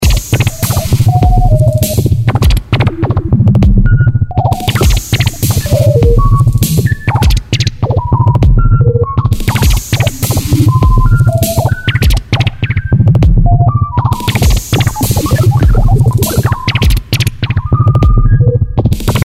Hier steuert der Mini-Sequencer im Drunk-Modus die Frequenz des Sample & Hold – Generators. Dadurch entstehen von Zeit zu Zeit schnelle Pieps-Cluster, die klingen wie der Computer aus Raumschiff Enterprise, wenn er angestrengt denkt.